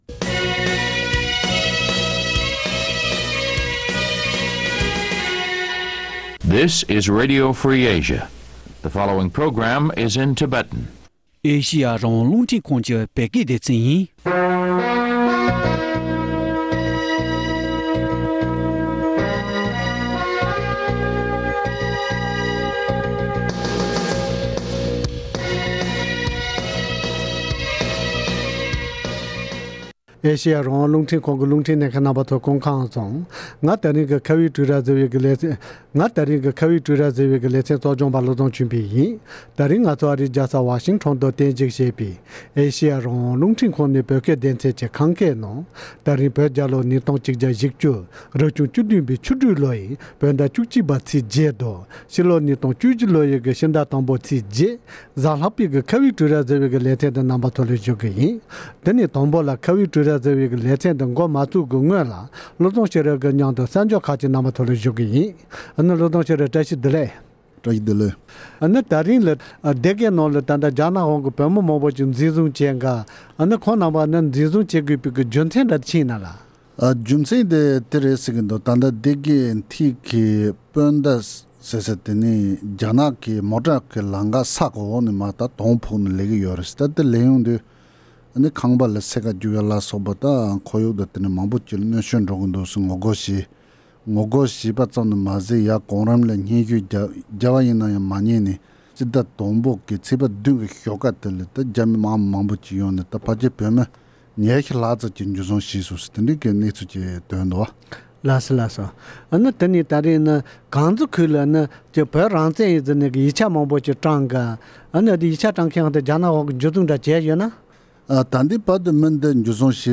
དེའི་སྐོར་འབྲེལ་ཡོད་མི་སྣ་ཁག་ཅིག་དང་ལྷན་དུ་གླེང་མོལ་ཞུས་པར་གསན་རོགས་གནང་།།